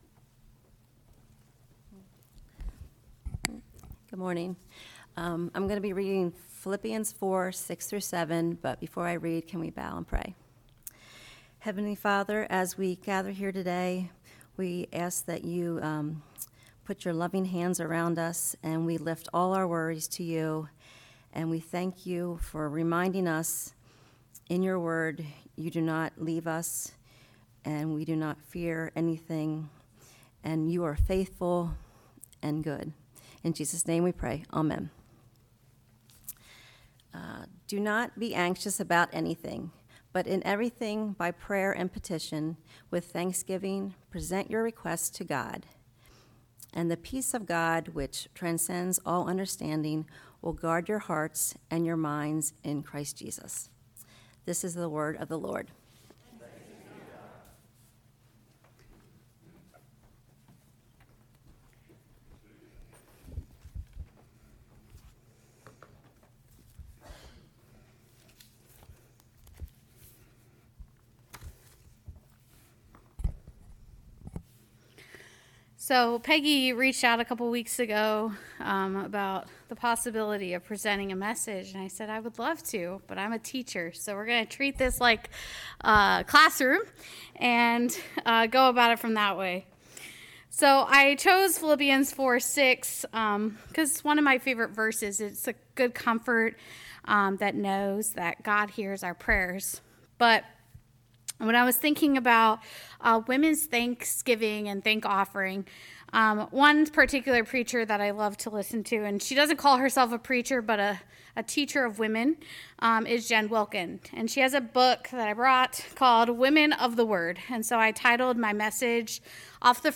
Passage: Philippians 4:6-7 Service Type: Women’s Thank Offering Service